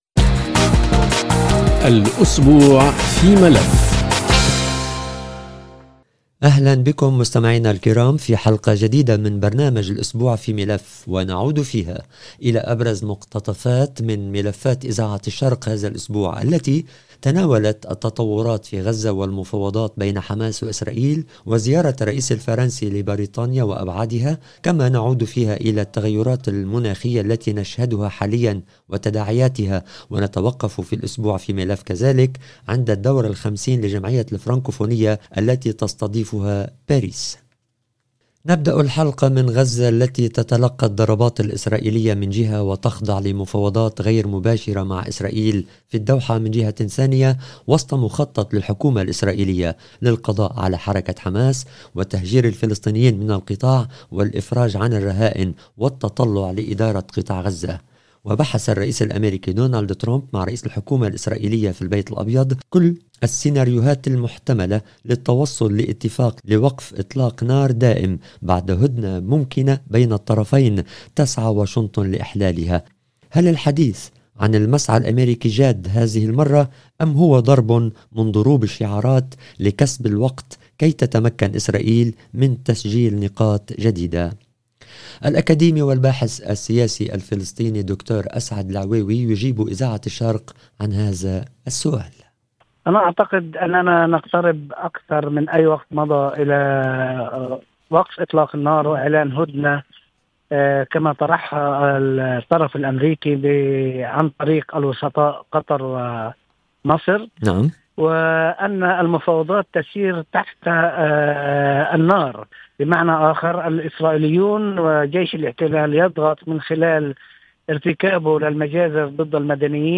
الأسبوع في ملف يستعرض مقتطفات من ملفات ومقابلات مع مسؤولين ومتخصصين في مجالات مختلفة. وحلقة هذا الأسبوع تمحورت حول تطورات ومفاوضات الهدنة في غزة، زيارة الرئيس الفرنسي لبريطانيا في أول زيارة لزعيم أوروبي لهذا البلد منذ بريكسيت، الدورة الخمسون للجمعية الفرنكوفونية في باريس وملف البيئة والتغير المناخي والكوارث الطبيعية.